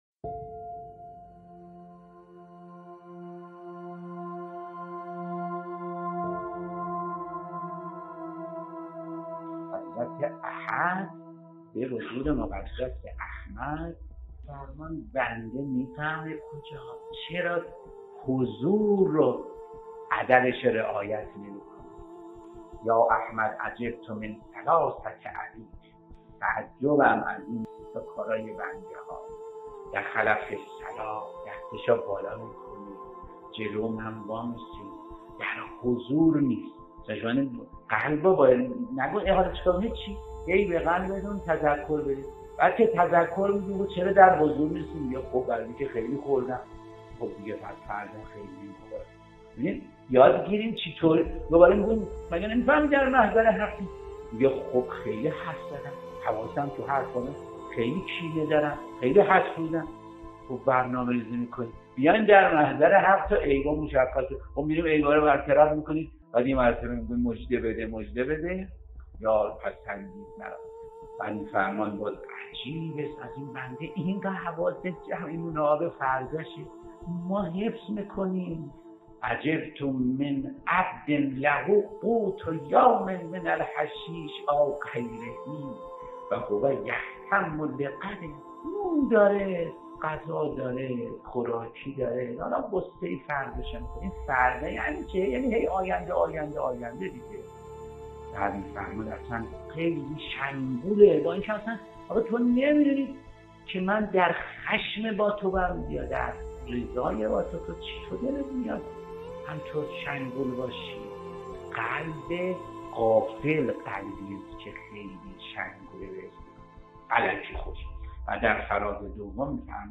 سخنرانی صوتی مذهبی